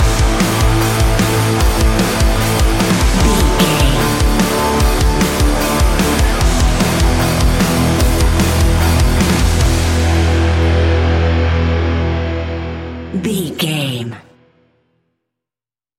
Ionian/Major
hard rock
heavy metal